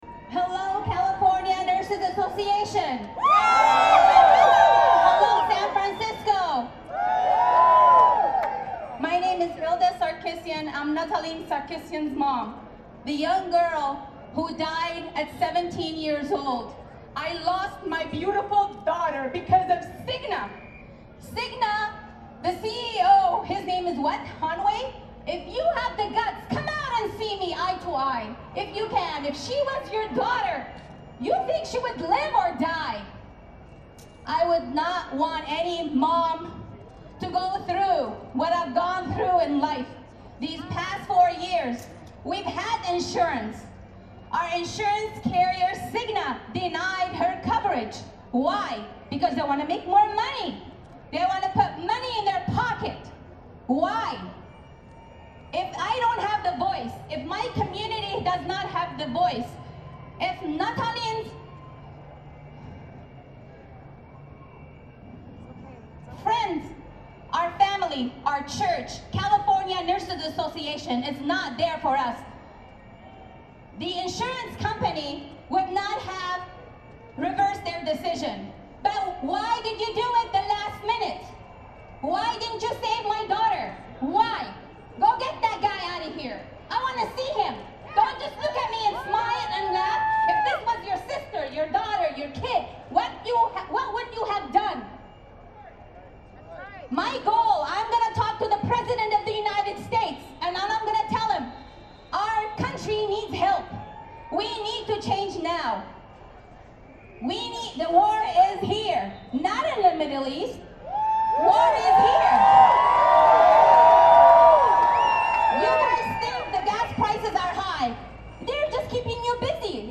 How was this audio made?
Many of the rally speakers testified about having been denied health care by insurers when suffering life-threatening diseases.